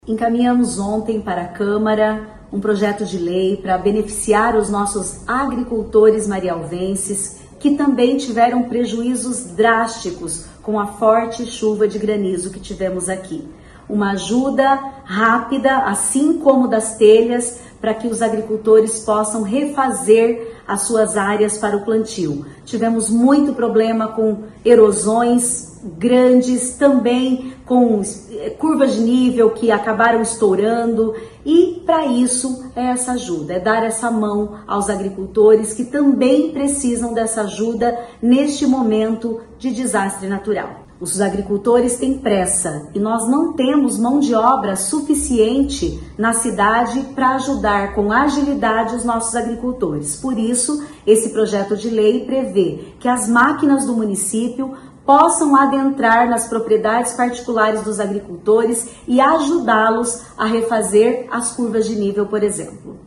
Ouça o que disse a prefeita Flávia Cheroni: